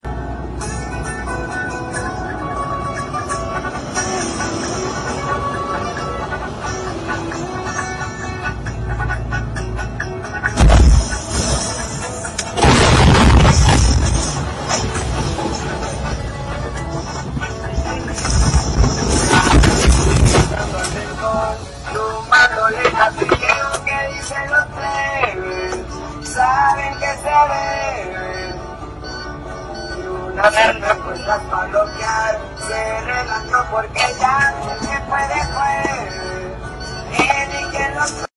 A Truckers Dash-cam Records 2 Rigs Meeting Head on in Front of Him